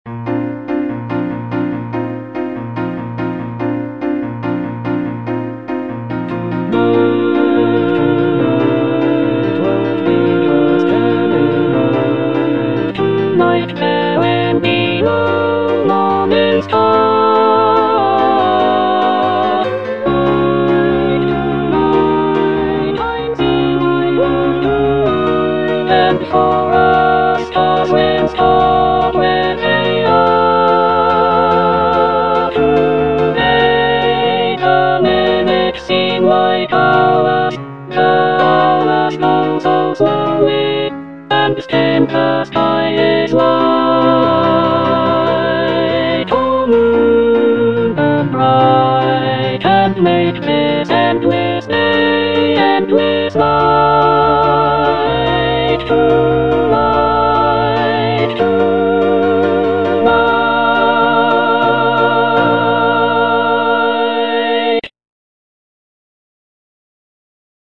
Alto (Emphasised voice and other voices) Ads stop